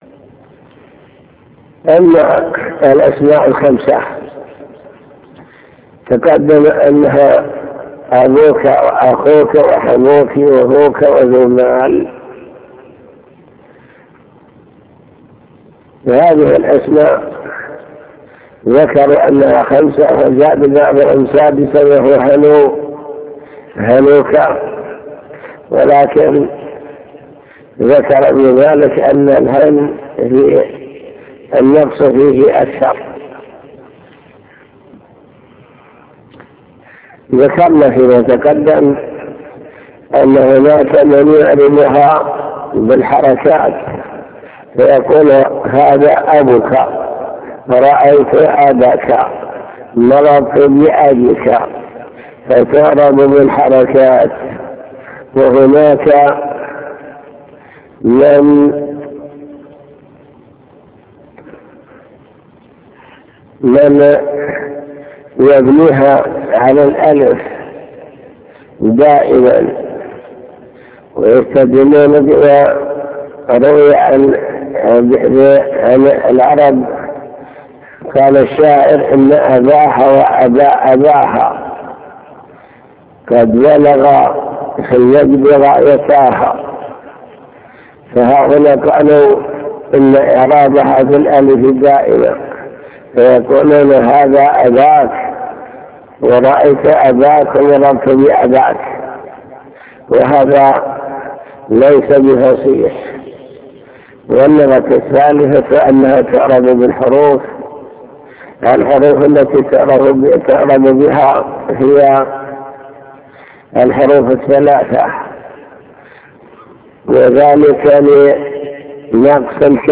المكتبة الصوتية  تسجيلات - كتب  شرح كتاب الآجرومية